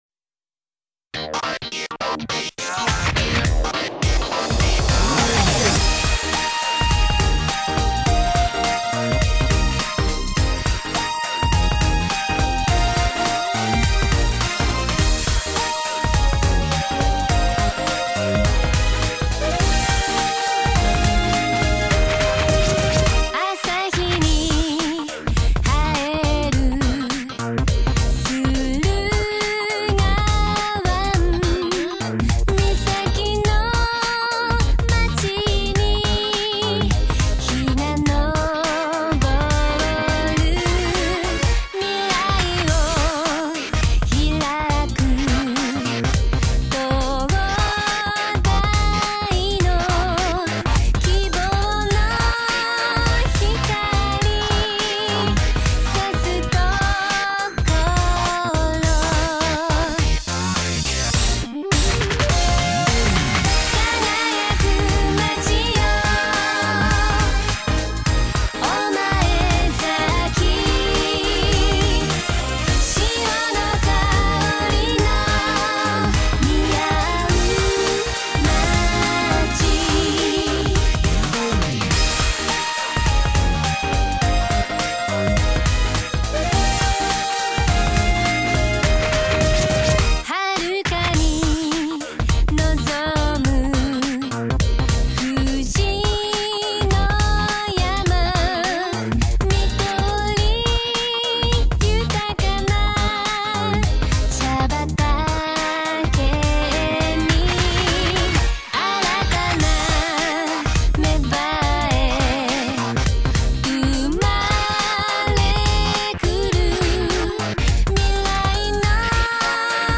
ダンスバージョン